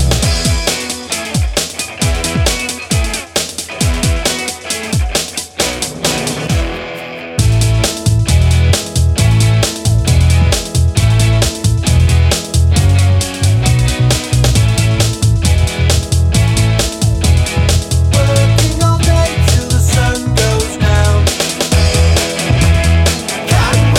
no Backing Vocals Comedy/Novelty 3:05 Buy £1.50